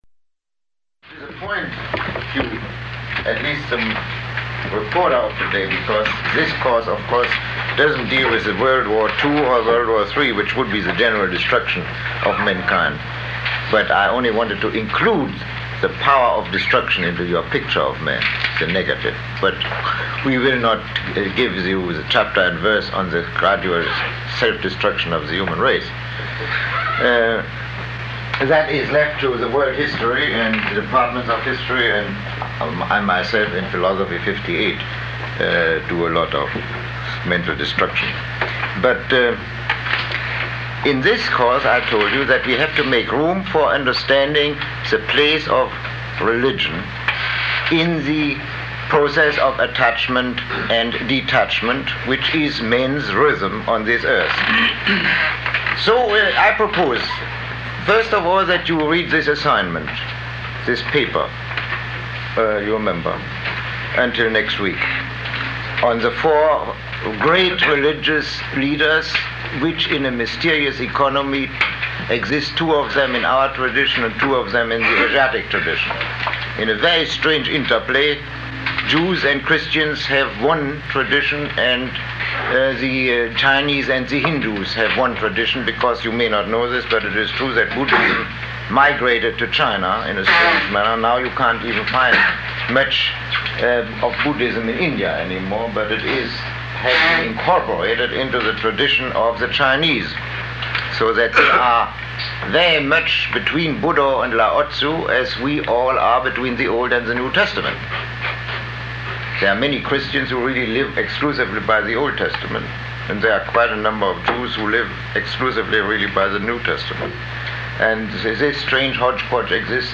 Lecture 20